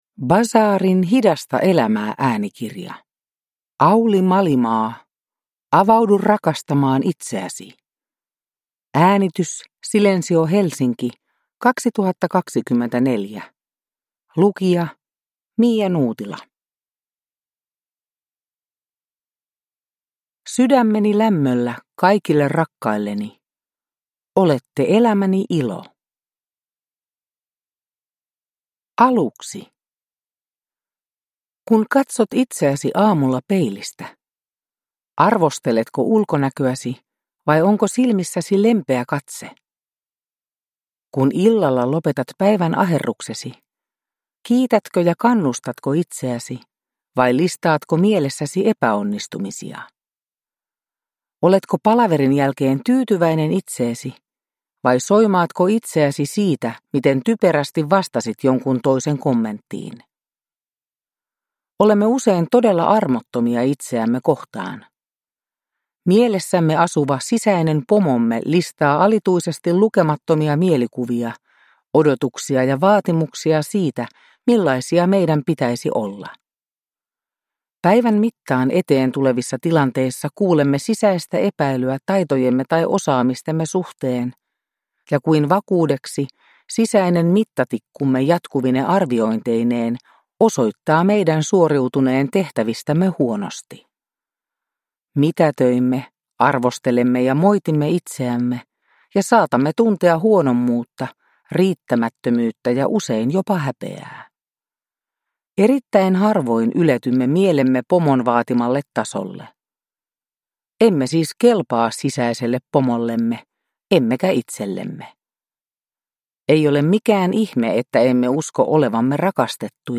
Avaudu rakastamaan itseäsi – Ljudbok